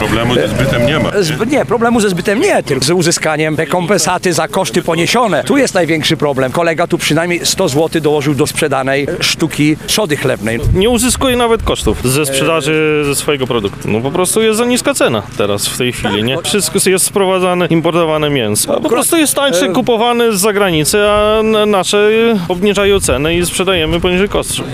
Istnieje obawa, że umowa z Mercosurem utrudni rolnikom zbyt swoich produktów i tym samym zarabianie na życie. Zapytaliśmy uczestników manifestacji jakie są obecne realia ich pracy:
Relacja